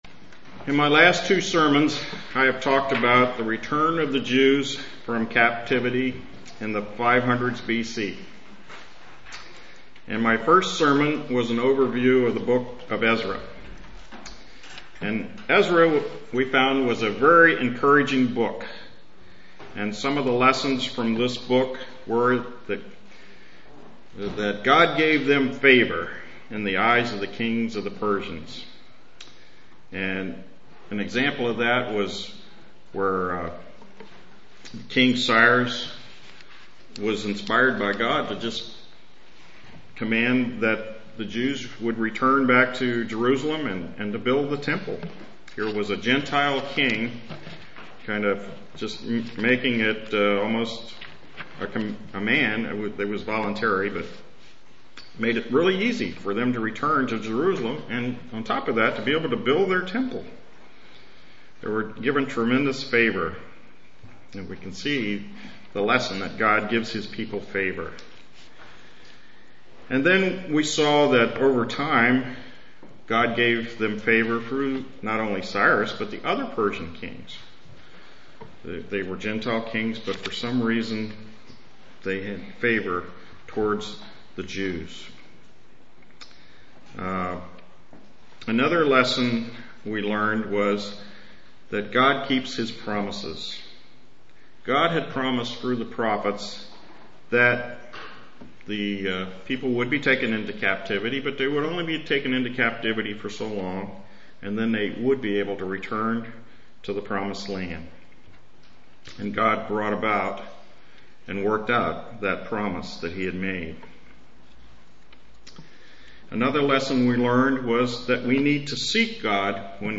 Given in Lewistown, PA
Print Lessons we can learn from Haggai UCG Sermon Studying the bible?